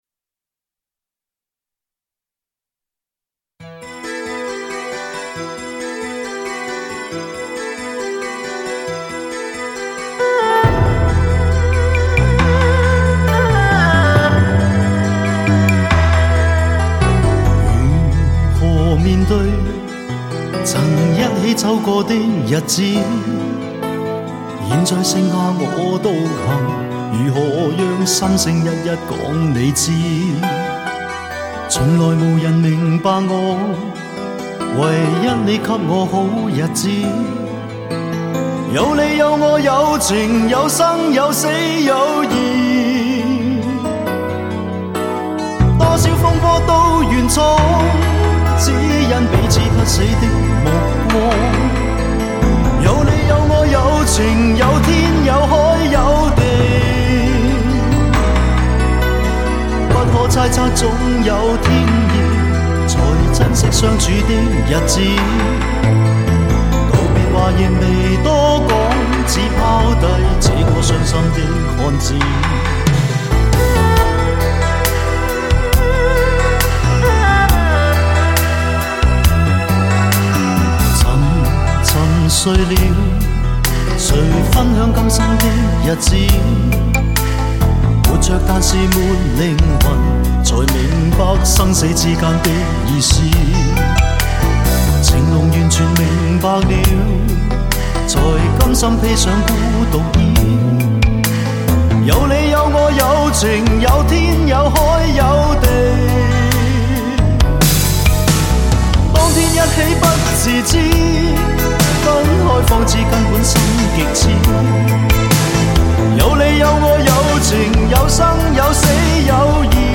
永不褪色 永恒传唱 经典粤语